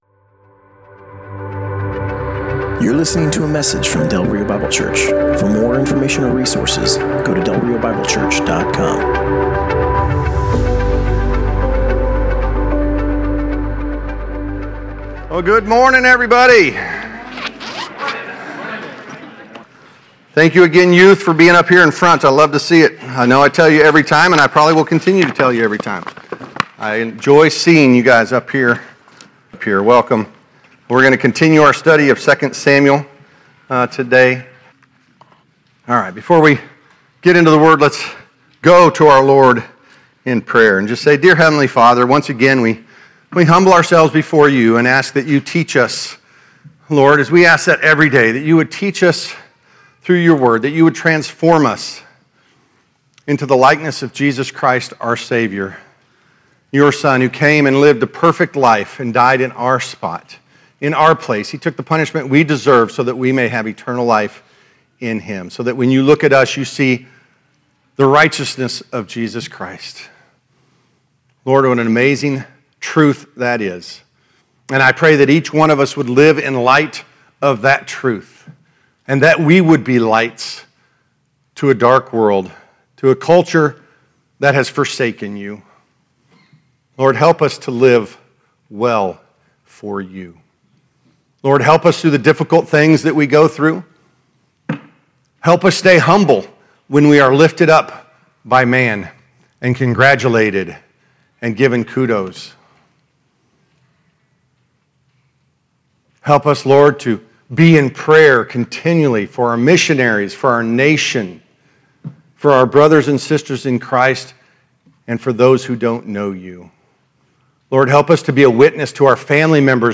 Passage: 2 Samuel 3: 22-39 Service Type: Sunday Morning